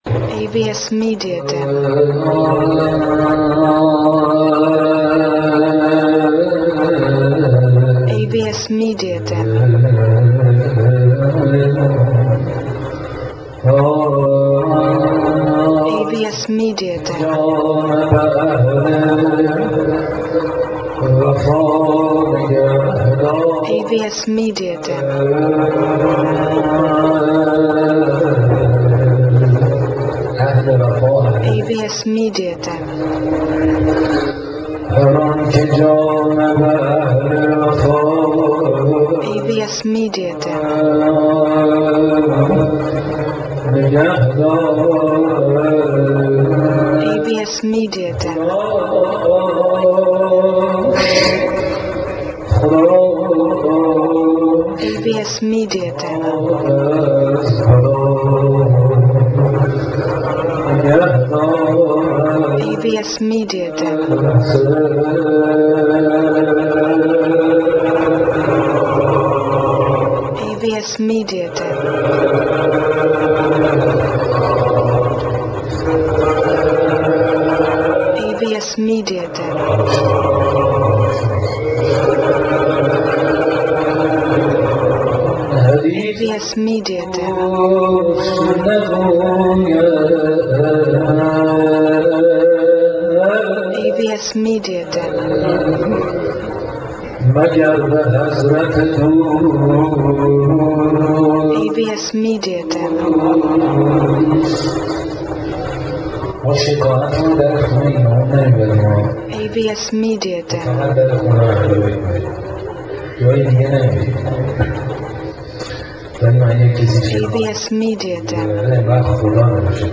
در مسجد ارک تهران برگزار شد.